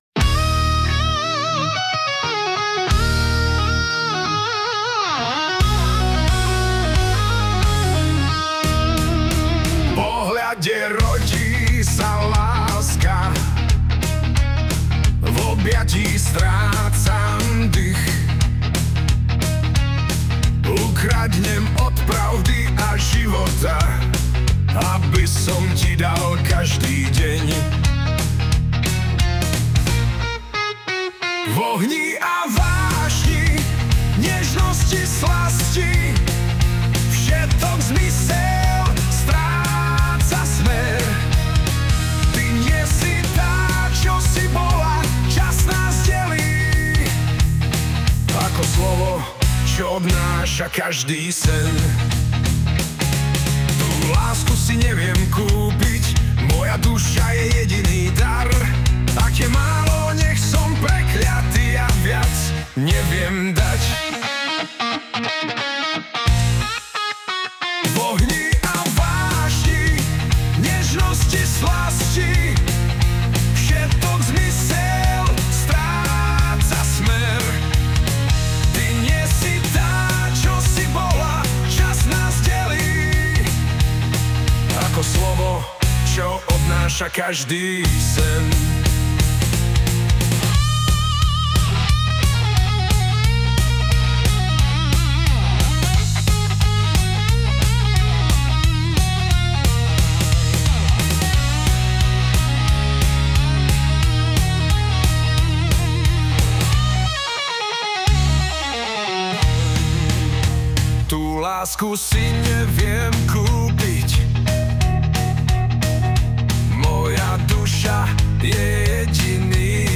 pěknej rockec :))